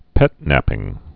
(pĕtnăpĭng)